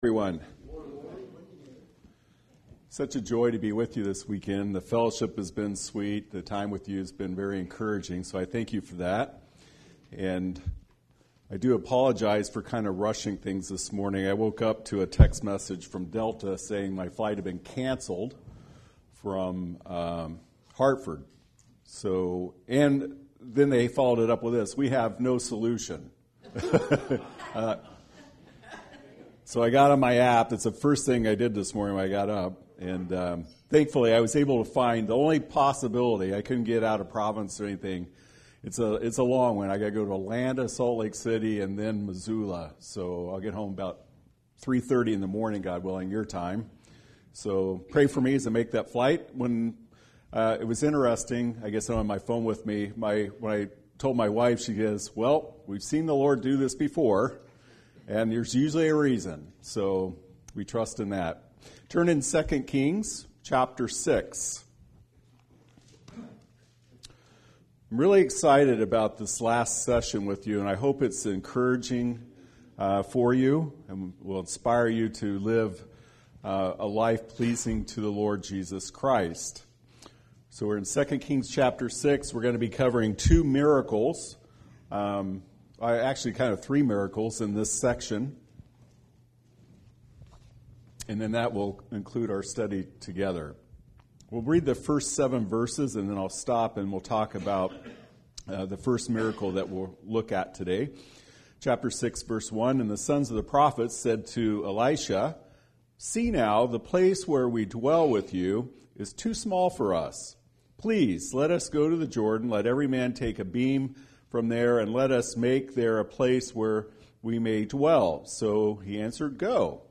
Listen to Weekly Message
Series: Spring Bible Conference